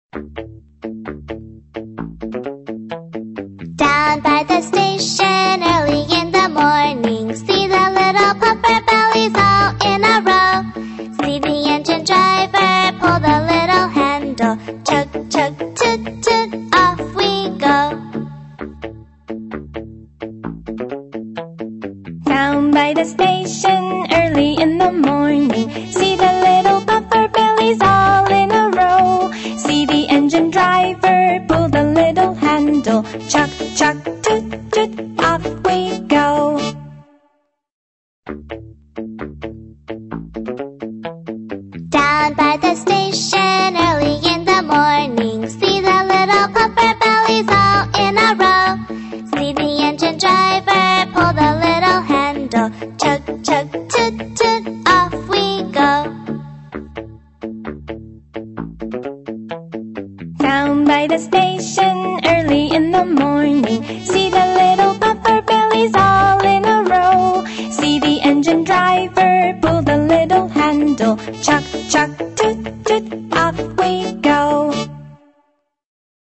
在线英语听力室英语儿歌274首 第38期:Down By the Station(2)的听力文件下载,收录了274首发音地道纯正，音乐节奏活泼动人的英文儿歌，从小培养对英语的爱好，为以后萌娃学习更多的英语知识，打下坚实的基础。